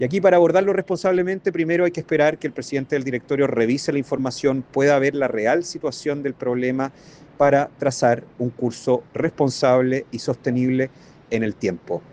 El diputado de la Unión Demócrata Independiente, Ricardo Neumann, opinó que es necesario contar primero con antecedentes desde el directorio de la cadena televisiva para analizar responsablemente la situación.